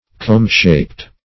Comb-shaped \Comb"-shaped`\, a.